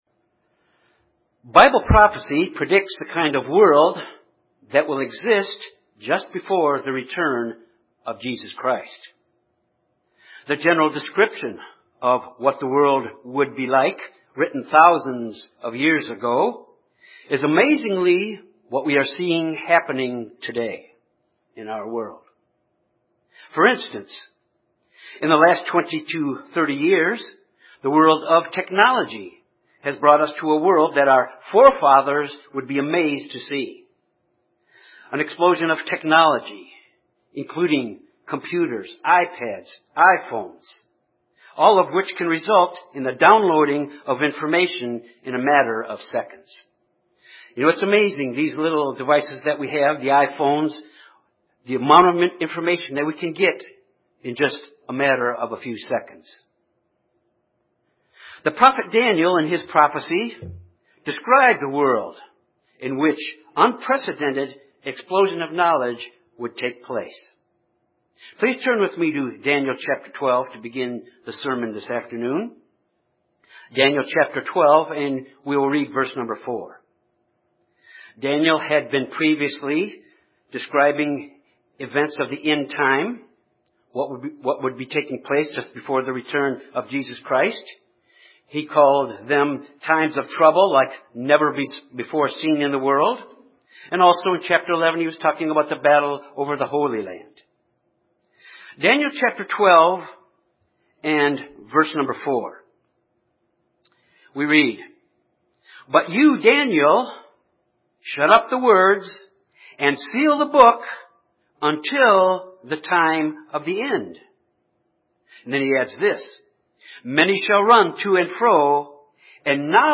This sermon examines the essential elements of Bible study by the use of three ways to revive and make our Bible study more effective.